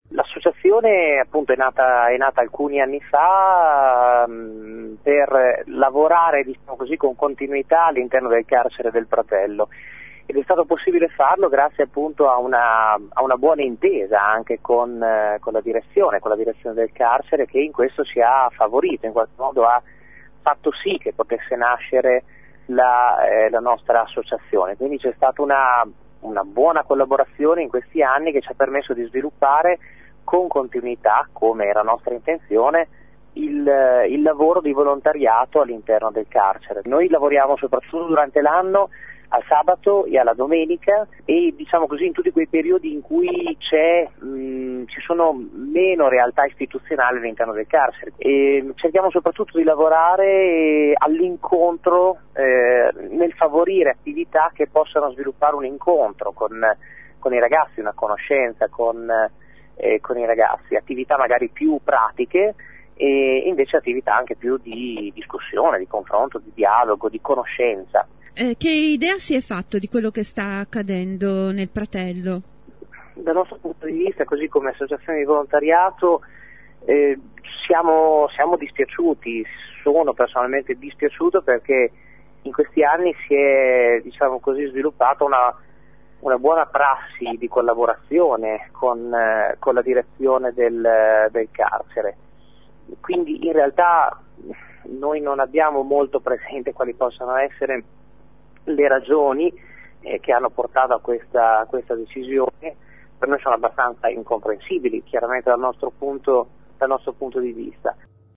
volontario post